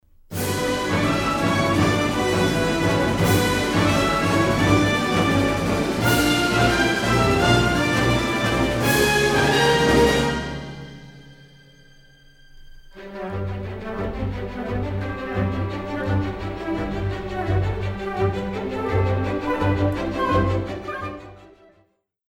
charming orchestral score